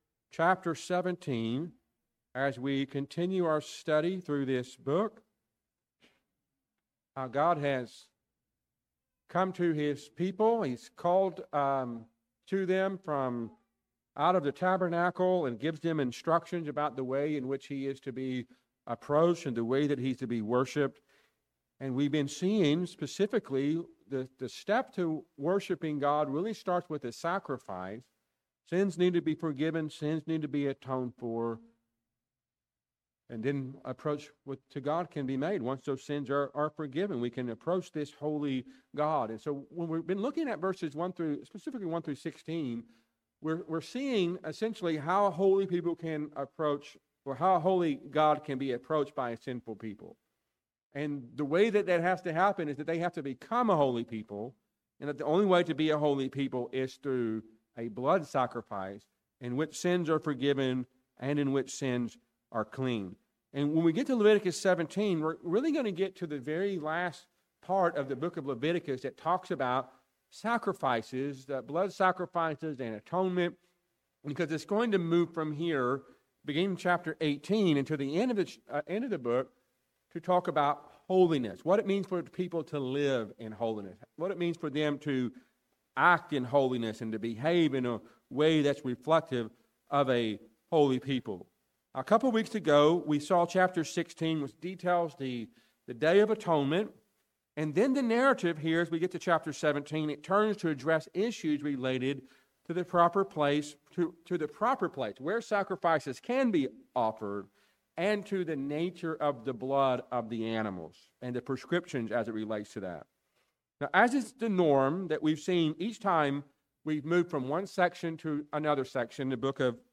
A sermon from Leviticus 17.